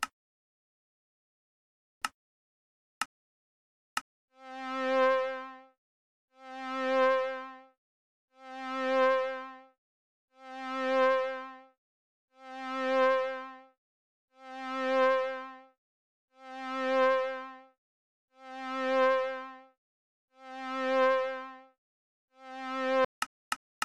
3.1 Sine waveform sound file
The audio cue is introduced by three clicks, followed by a constant tone fluctuating in volume as a sine wave. The 10th sound wave is interrupted at peak volume and immediately followed by click sounds to indicate the end of the sound cue.
sounds_sin_function.ogg